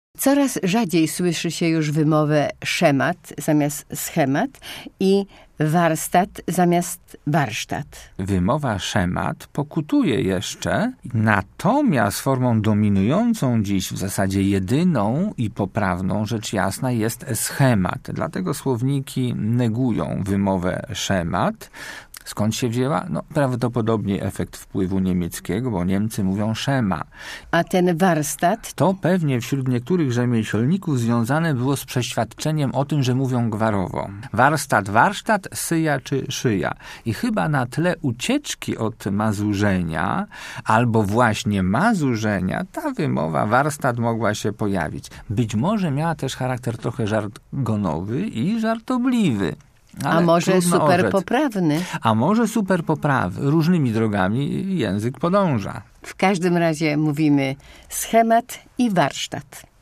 Coraz rzadziej już słyszy się wymowę szemat czy warstat.